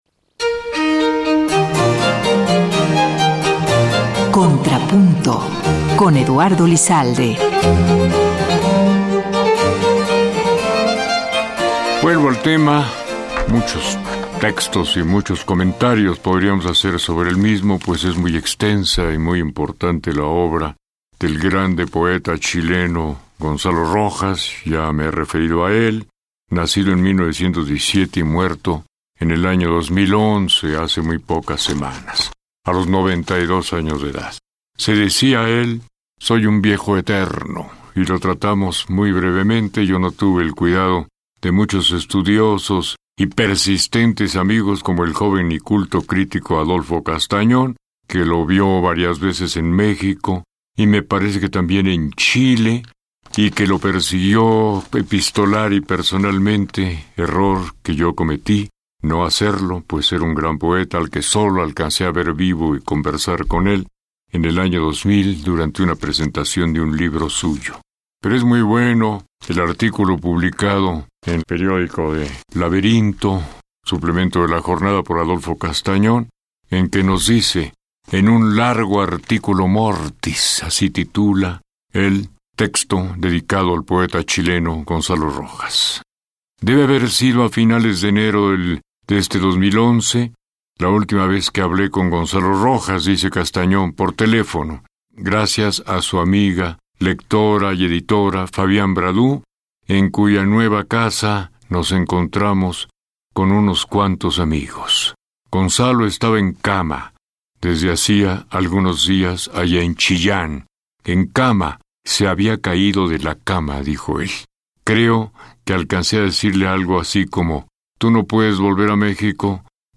Su conocimiento profundo sobre ópera y los más variados temas que conforman la cultura nacional y universal, su sensible e inigualable pluma, su rotunda y contundente voz, ensanchan las transmisiones de la radio pública y engalanan las emisoras del IMER, especialmente Opus 94.5 FM.